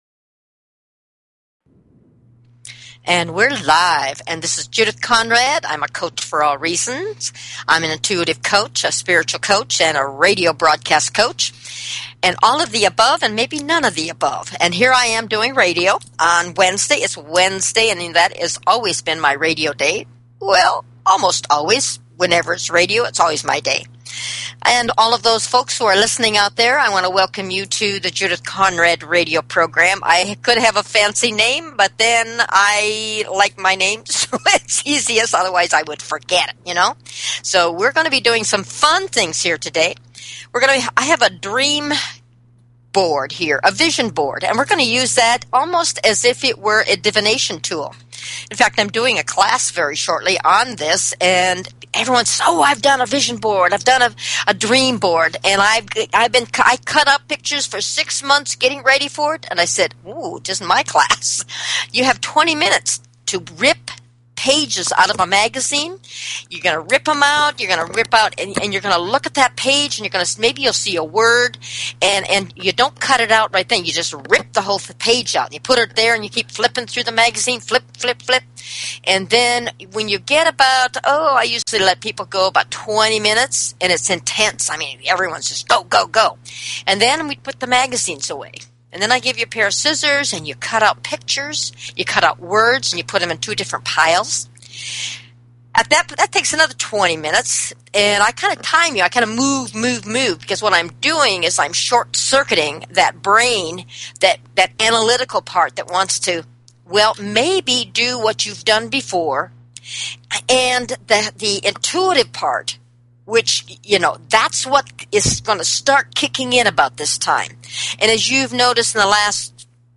Talk Show Episode
What a philosophical discussion we had.